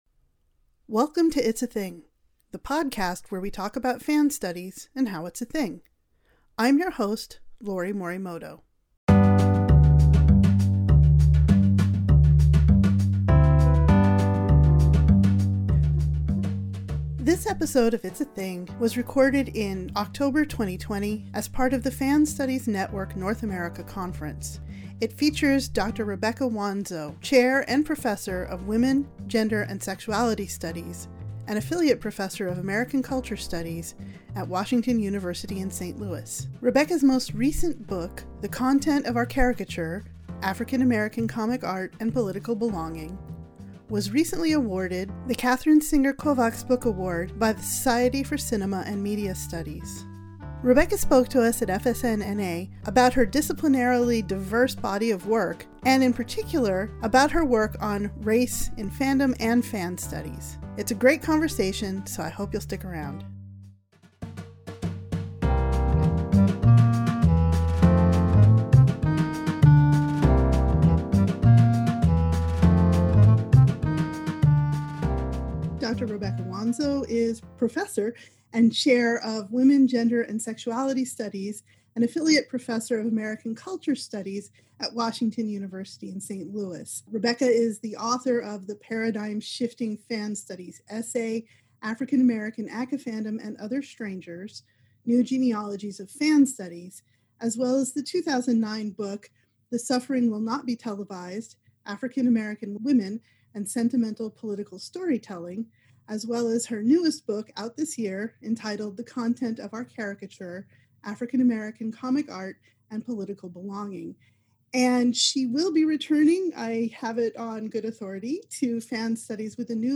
This Zoom conversation was the keynote event of the 2020 Fan Studies Network North America conference in October 2020